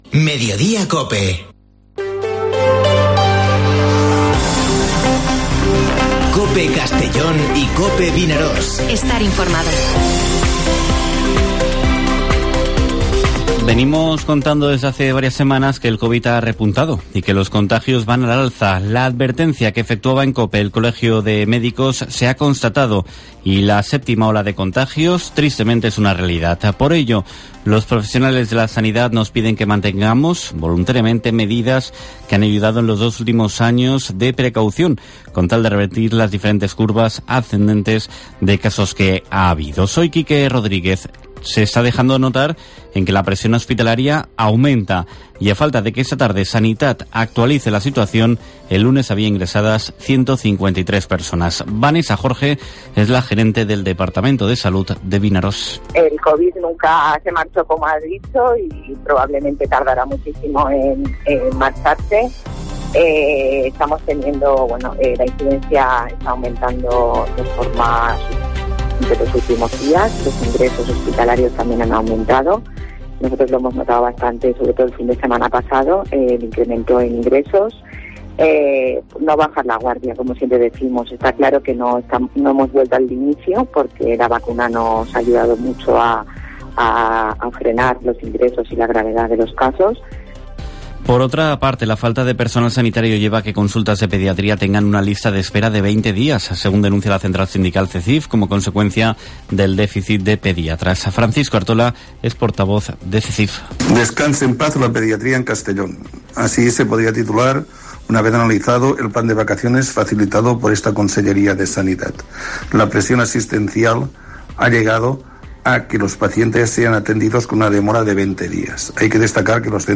Informativo Mediodía COPE en la provincia de Castellón (08/07/2022)